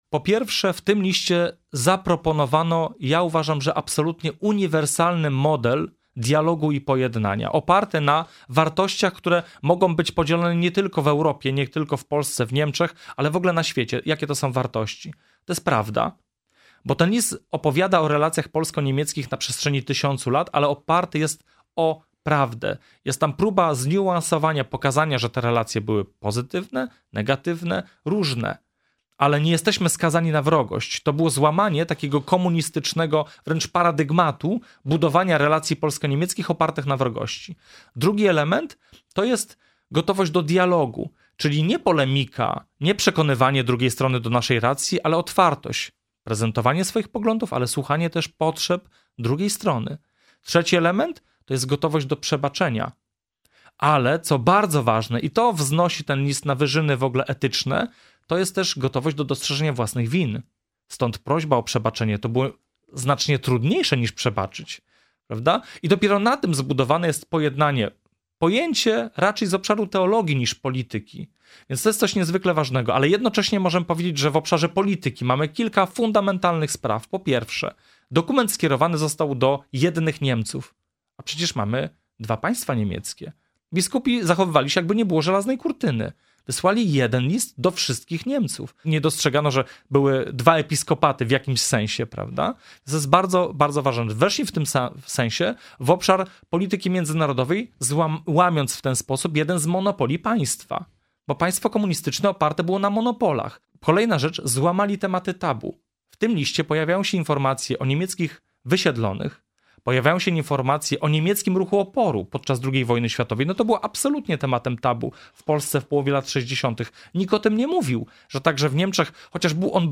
Mówi dr hab.